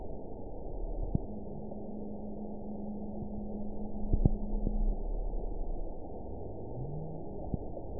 event 922624 date 02/10/25 time 00:57:00 GMT (2 months, 3 weeks ago) score 9.11 location TSS-AB04 detected by nrw target species NRW annotations +NRW Spectrogram: Frequency (kHz) vs. Time (s) audio not available .wav